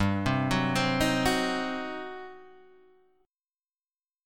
G Major 7th Suspended 4th Sharp 5th